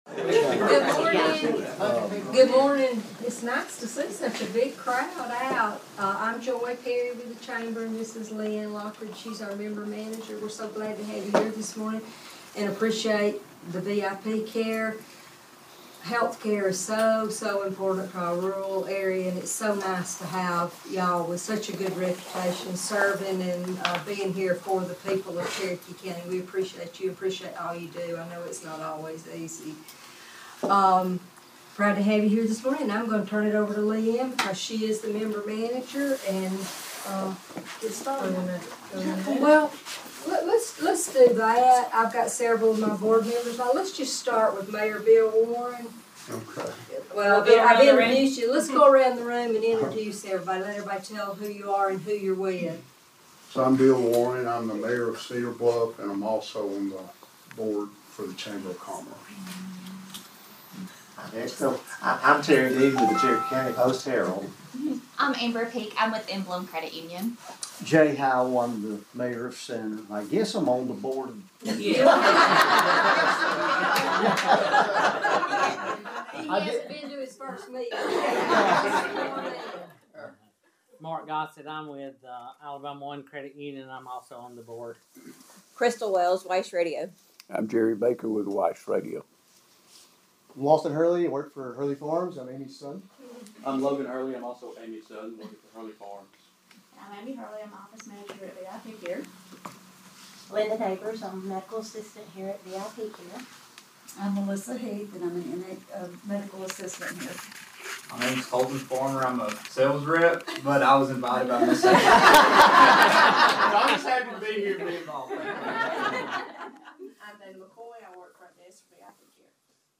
VIP Care operates Monday through Friday from 8 a.m. to 5 p.m. Following brief remarks, attendees gathered outside for the ceremonial ribbon cutting and were invited back inside for tours ahead of the public open house.